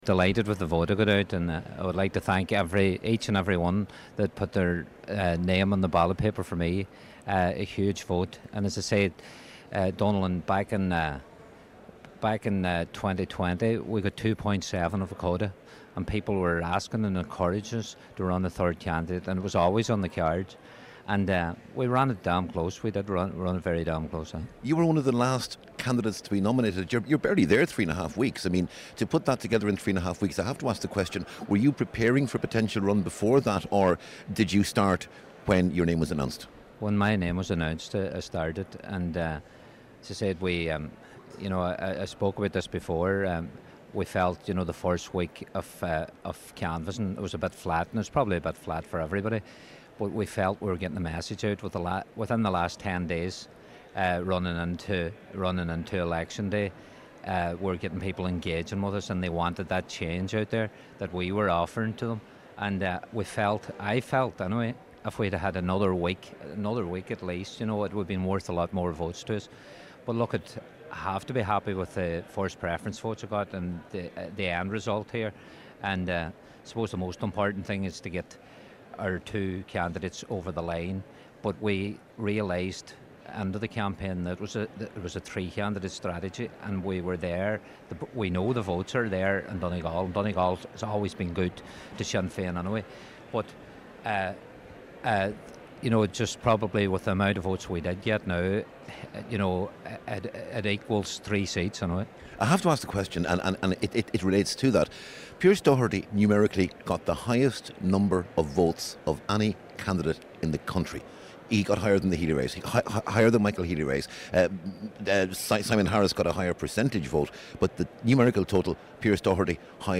He says the support for Sinn Féin in Donegal is strong: